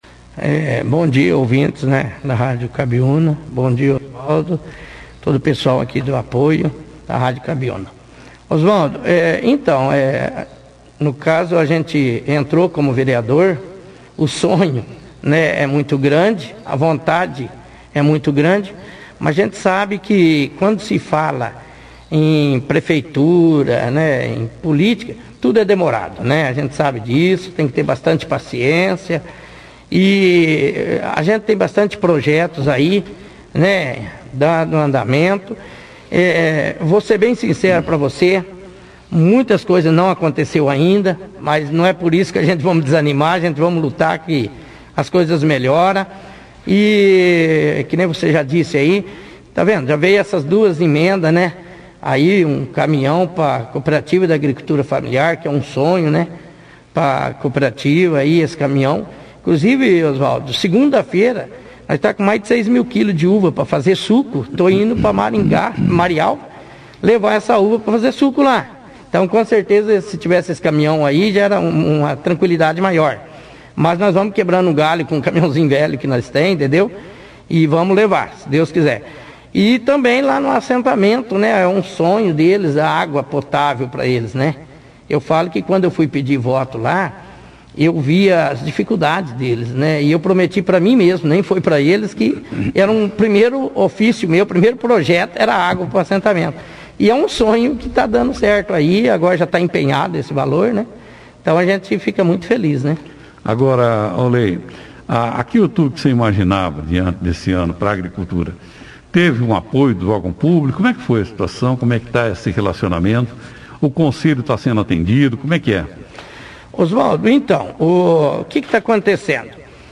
participaram da edição deste sábado, 08/01, do jornal Operação Cidade